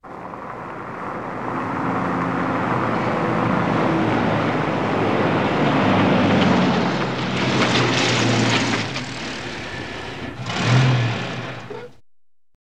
Гул мотора пикапа